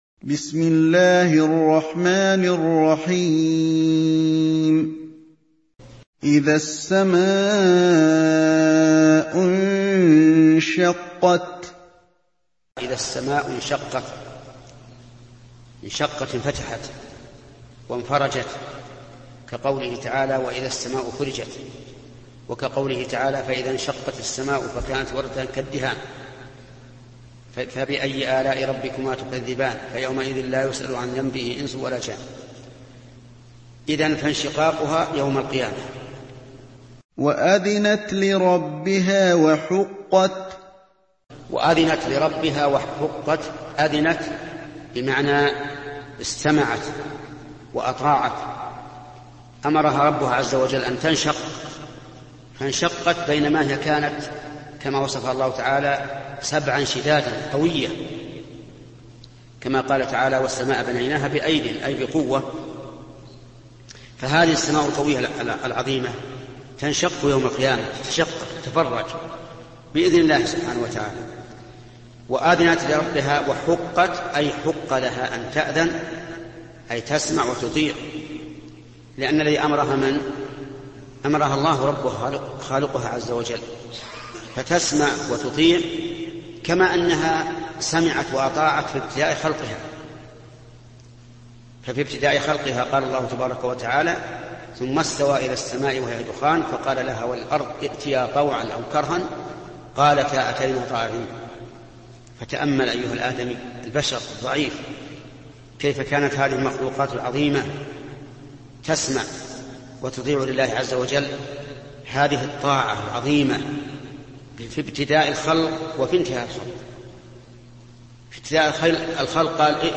الدرس الثامن: من قوله: تفسير سورة الانشقاق، إلى: نهايةنهاية تفسير سورة الانشقاق.